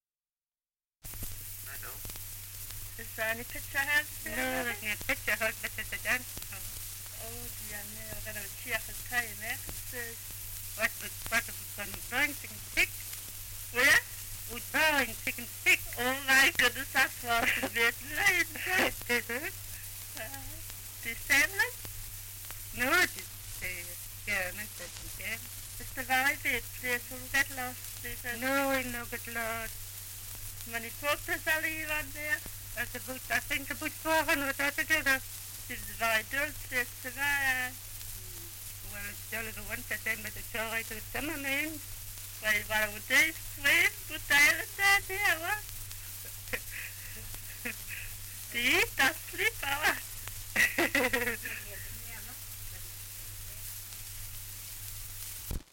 2 - Dialect recording in Newbiggin-by-the-Sea, Northumberland
78 r.p.m., cellulose nitrate on aluminium